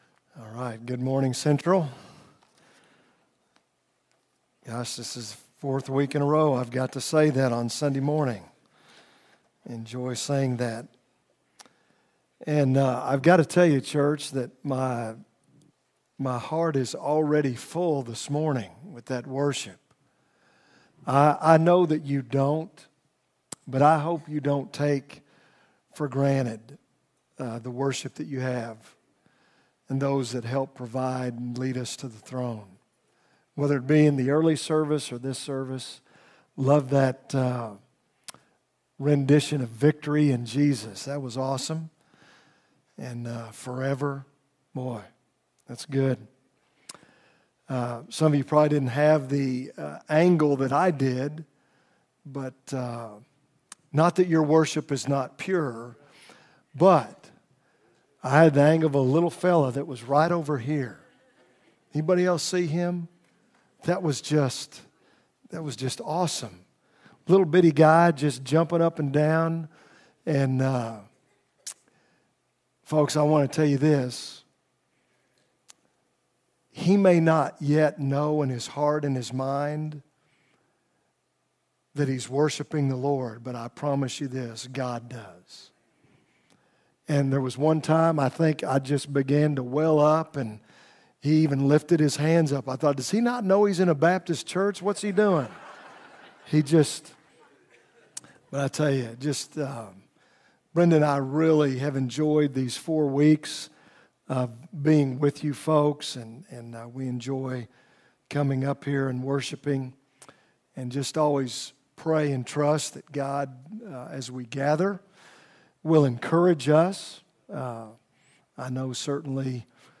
From Series: "2018 Sermons"